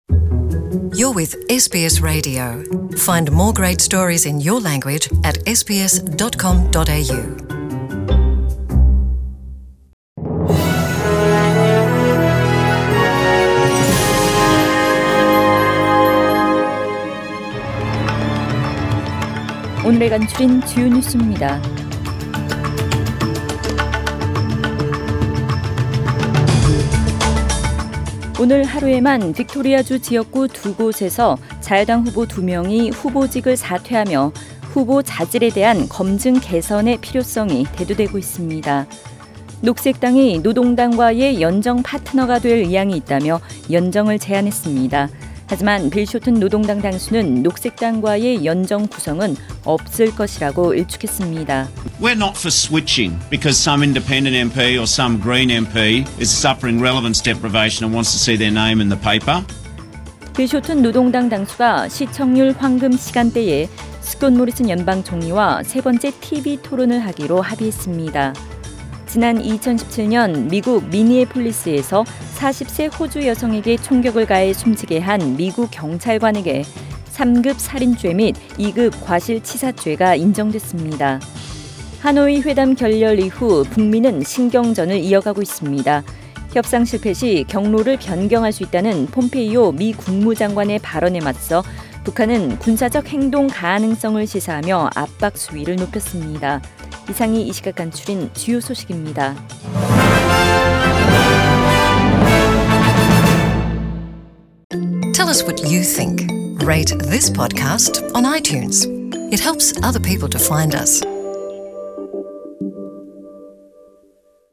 SBS 한국어 뉴스 간추린 주요 소식 – 5월 1일 수요일
2019년 5월 1일 수요일 저녁의 SBS Radio 한국어 뉴스 간추린 주요 소식을 팟 캐스트를 통해 접하시기 바랍니다.